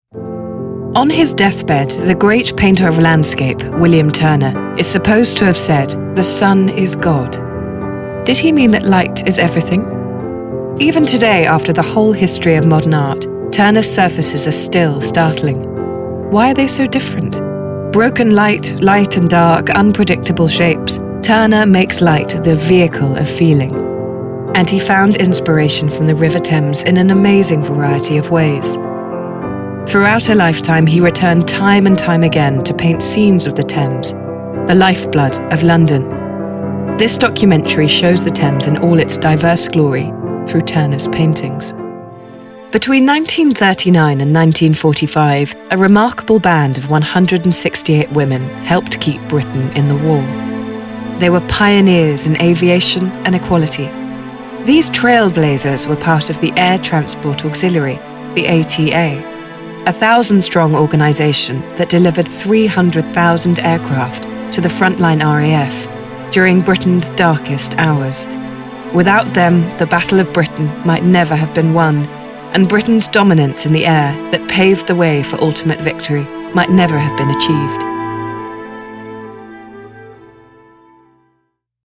RP. Warm, gentle and intelligent voice.
Commercial Audio: Commercials Edit